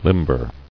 [lim·ber]